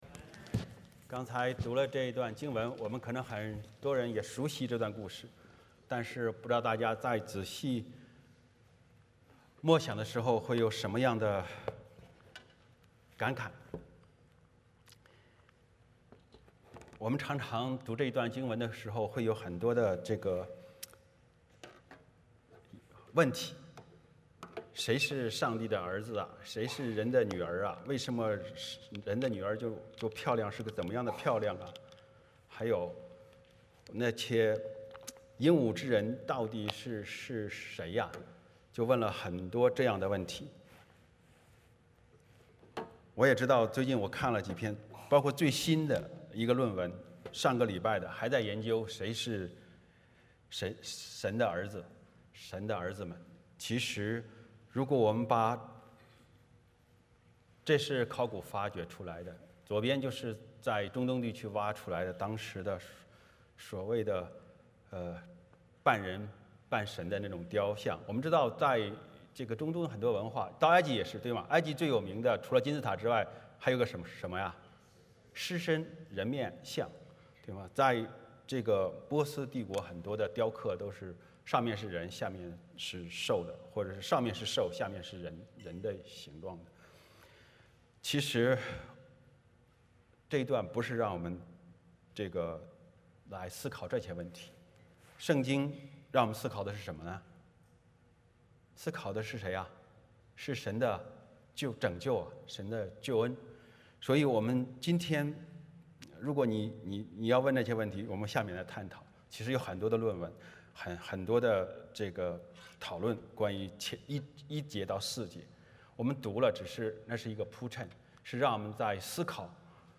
22 Service Type: 主日崇拜 欢迎大家加入我们的敬拜。